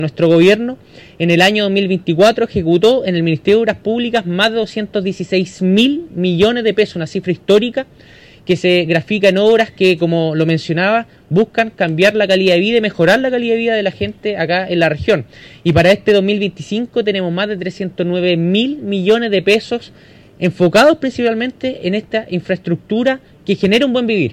Los representantes del Ejecutivo se reunieron en el piso 12 del Gobierno Regional, liderados por el delegado Presidencial, Eduardo Abdala, para dar cuenta del progreso logrado en la zona y todo el país.
Por su parte, el seremi de Obras Públicas, Patricio Poza, dijo que la cartera ejecutó más de 216 mil millones de pesos el año pasado y para este 2025 -dijo- eso aumentó a más de 309 mil millones de pesos.